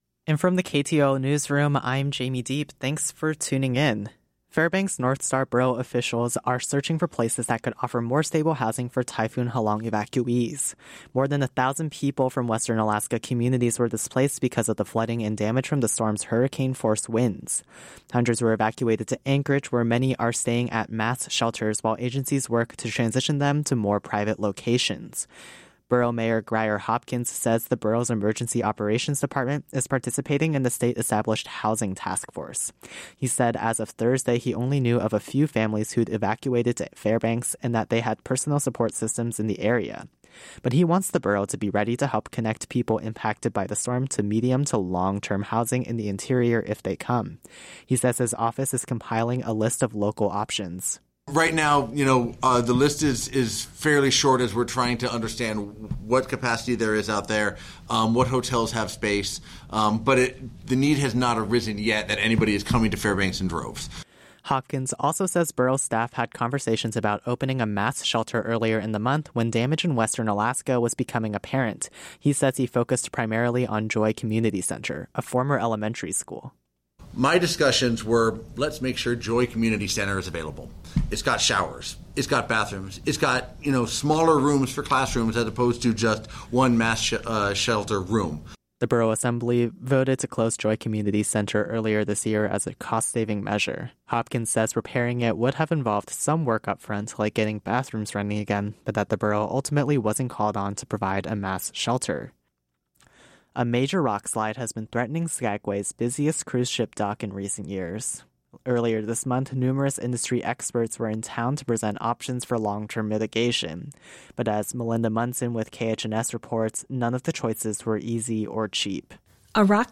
Newscast – Monday, Oct. 27, 2025